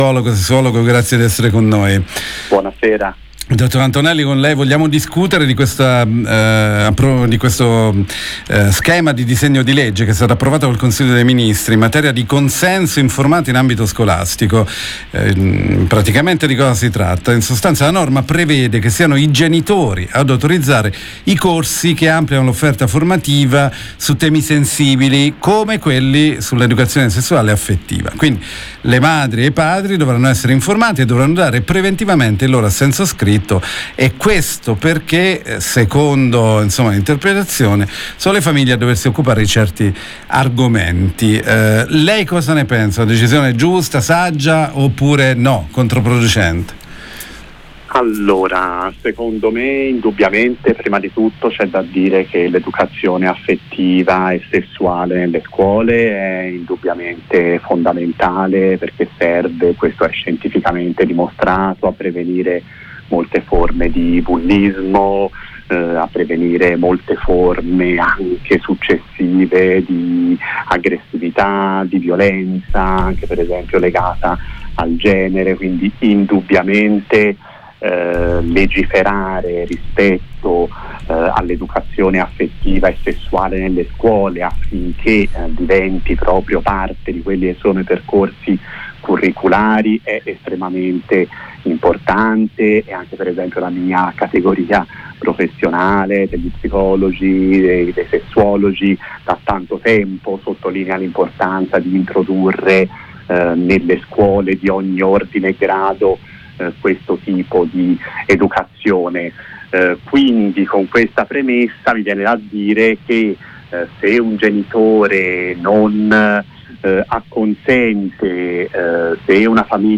Lo abbiamo intervistato